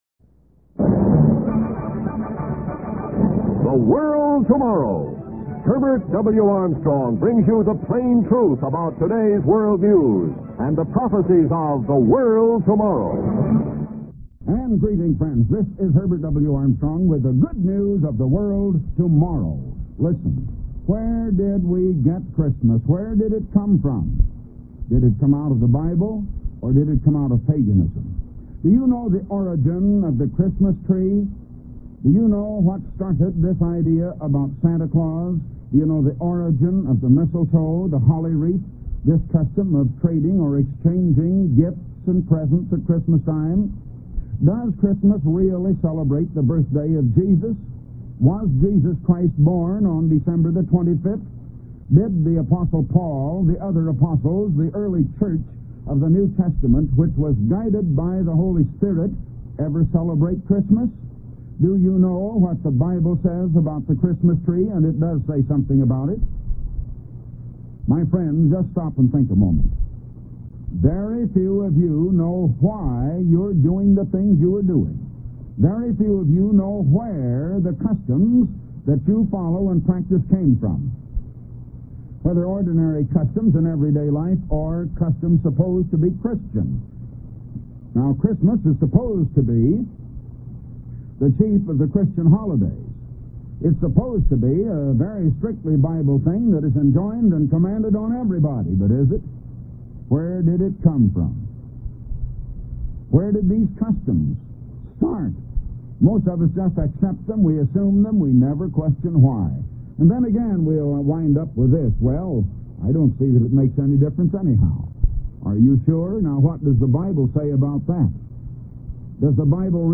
Type: Radio Broadcast